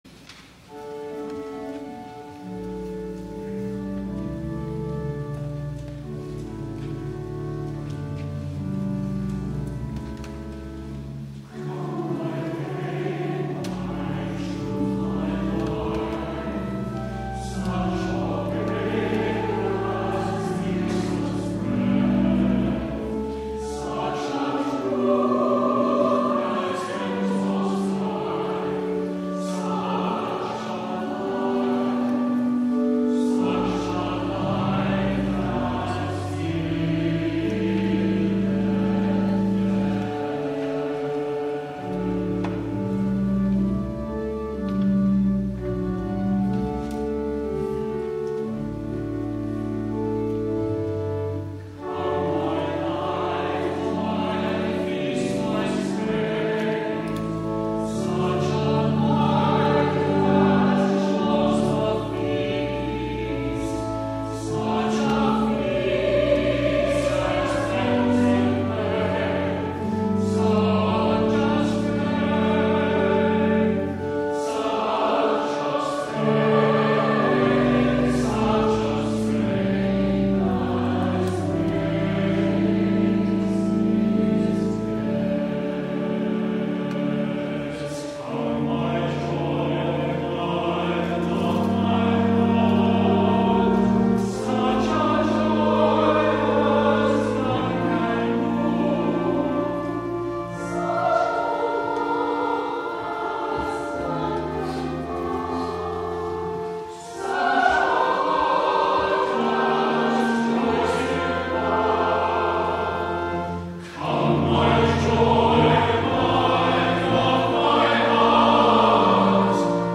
WORSHIP FEBRUARY 9, 2014
THE ANTHEM